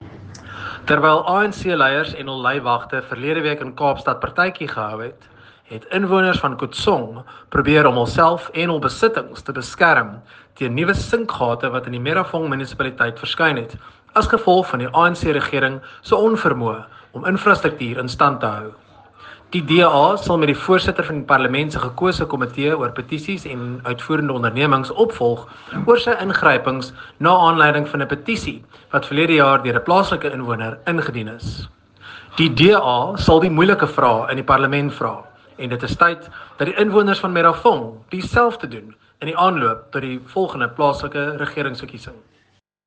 Note to Editors: Please find English and Afrikaans soundbites by Nicholas Gotsell MP
Nicholas-Gotsell-MP_Afrikaans_Sinkgate-in-Merafong.mp3